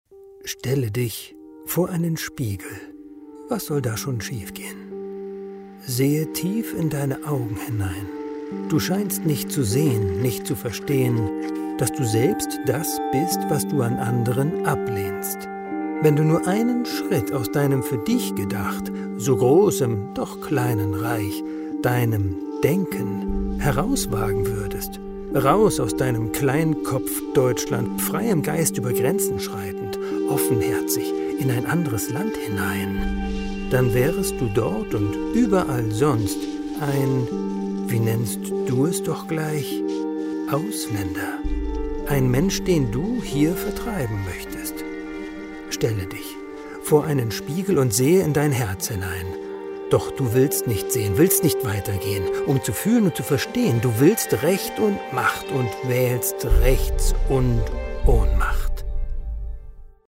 GedankenKlang – Hörstück °
Uraufführung in der Akademie der Künste, Berlin